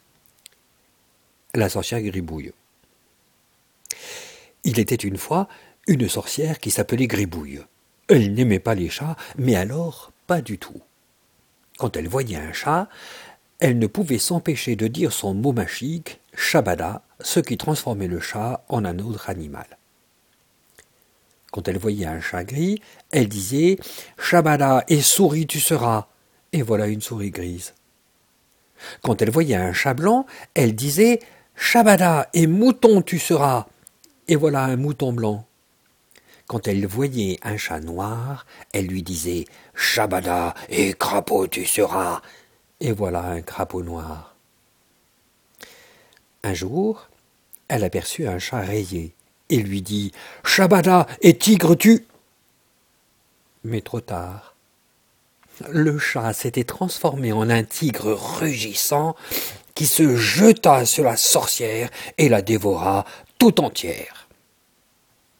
Elle permettra aux enseignants, orthophonistes, rééducateurs, psychologues ou chercheurs d’évaluer le niveau de compréhension d’enfants de 5 à 8 ans qui écoutent la lecture à haute voix de trois récits non illustrés (cf. les parties I et II du document de présentation de l'épreuve et les enregistrements sonores).